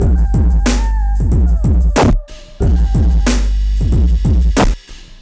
BEAT 2 9201R.wav